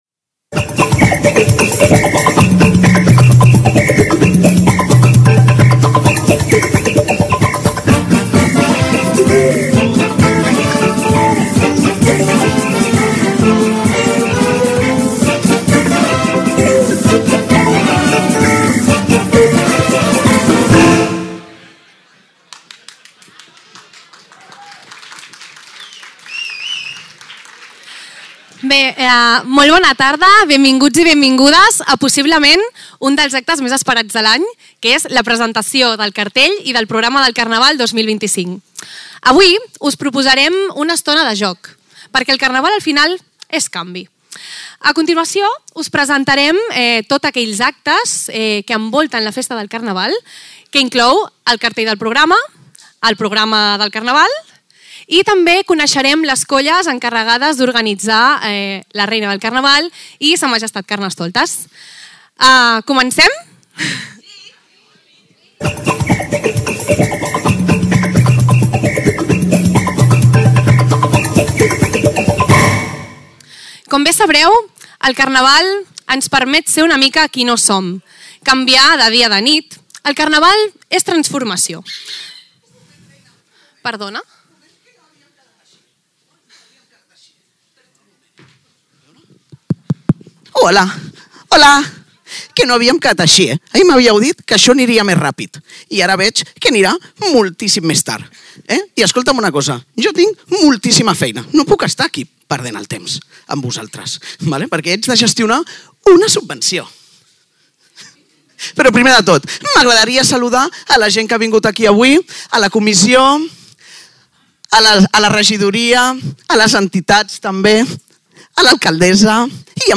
Escolteu l’acte íntegre